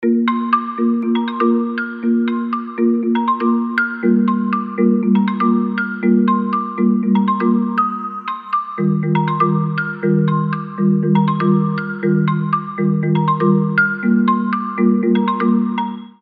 • Качество: 320, Stereo
мелодичные
без слов
Electronica
Красивая мелодия на будильник или звонок